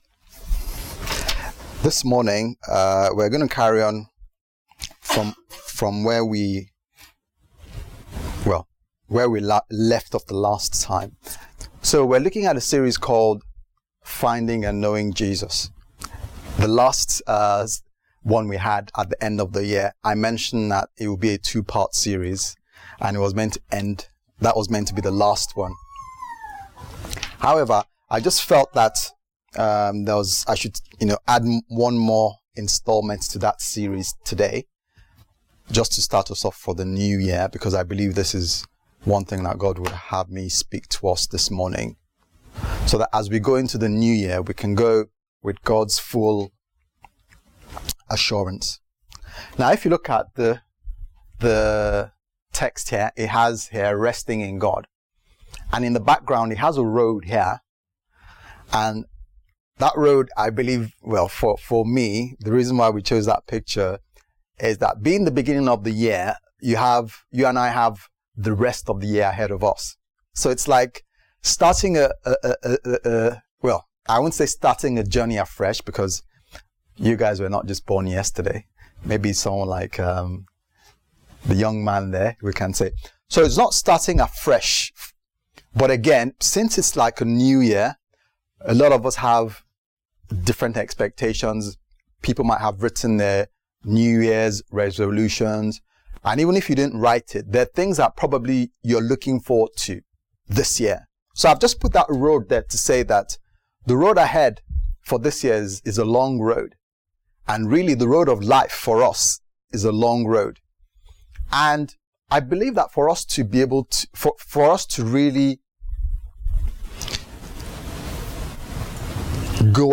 Finding & Knowing God Service Type: Sunday Service « Finding & Knowing God